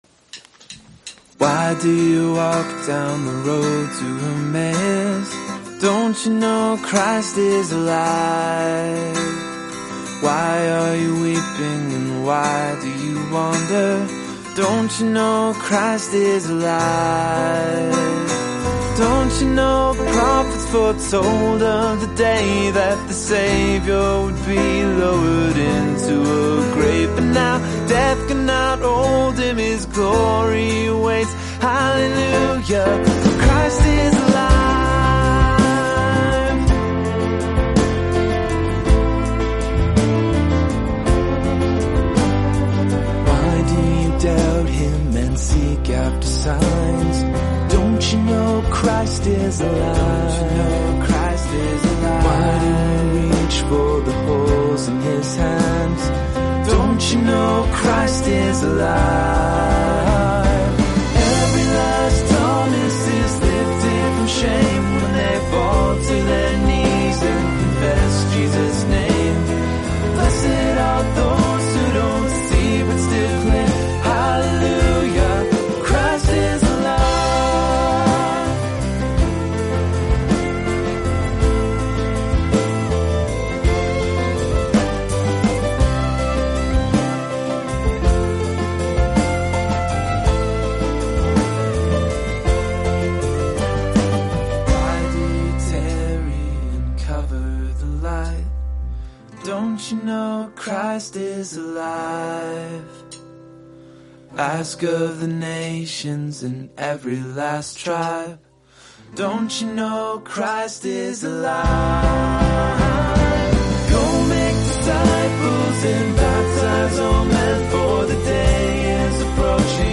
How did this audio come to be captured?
Live Session